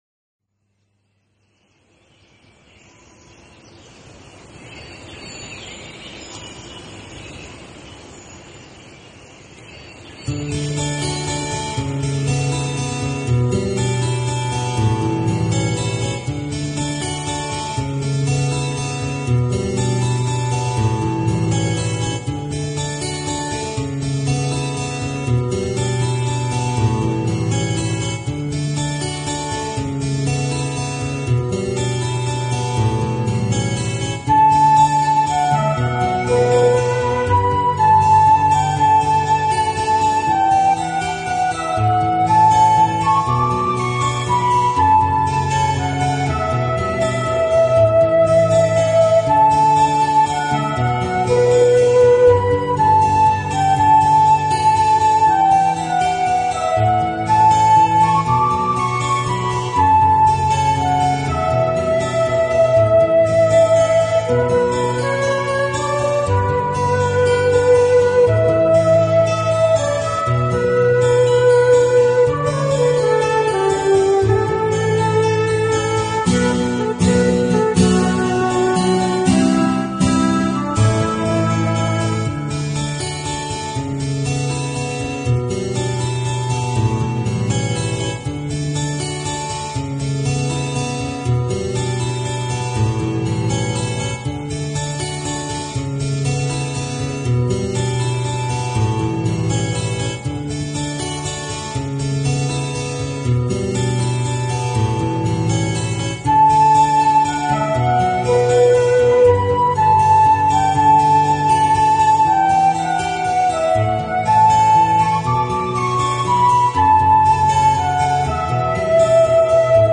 Categories：New Age
Music instruments： Piano， Guitar，Flute - Dizi
类型: New Age